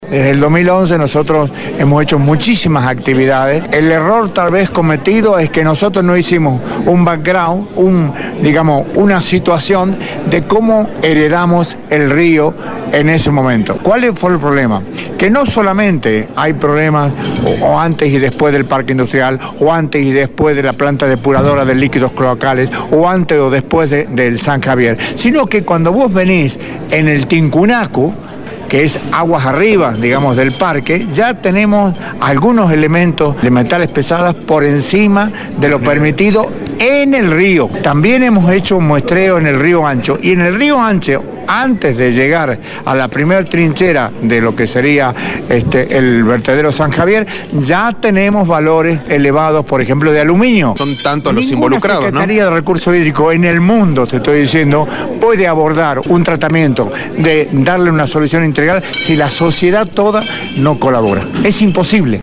«Desde el 2011 nosotros hemos hecho muchísimas actividades, el error tal vez cometido es que nosotros no hicimos un background, de la situación de cómo heredamos el río en ese momento, ¿cuál fue el problema? que no solamente hay problemas o antes o después del Parque Industrial, o antes o después de la planta depuradora de líquidos cloacales, o antes o después del Vetedero San Javier, sino que cuando vos venís en el Tincunacu, que es aguas arriba del parque, ya tenemos algunos elementos de metales pesados por encima de lo permitido en el río, también hemos hecho en un muestreo en el Río Ancho y en el Río Ancho antes de llegar a la primer trinchera, de lo que sería el vertedero San Javier, ya tenemos valores elevados por ejemplo de aluminio«, manifestó Fuentes a Radio Dinamo.
EL-SECRETARIO-DE-RECURSOS-HIDRICOS-DE-LA-PCIA-INGENIERO-ALFREDO-FUERTES-SE-REFIRIO-A-LA-CONTAMINACION-DEL-ARENALES.mp3